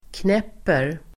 Uttal: [kn'ep:er]